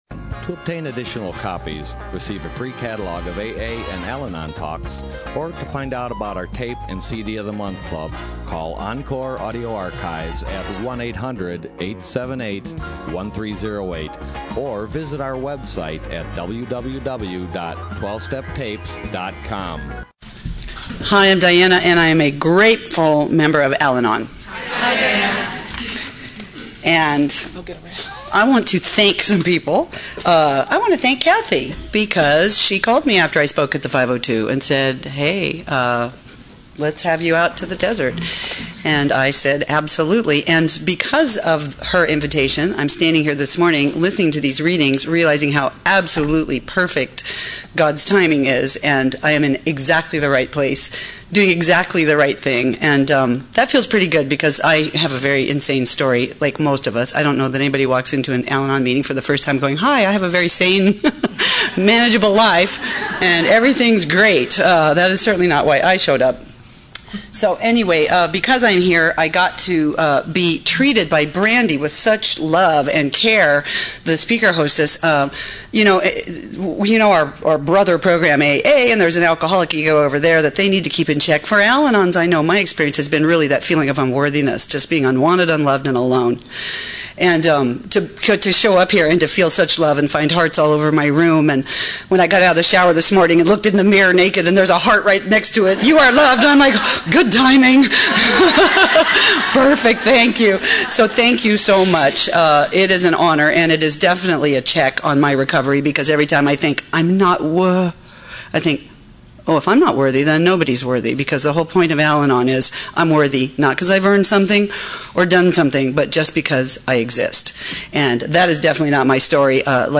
SoCAL AA Convention